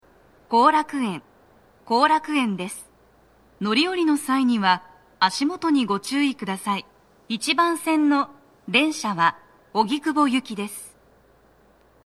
足元注意喚起放送が付帯されており、粘りが必要です。
到着放送1
Panasonic天井型での収録です。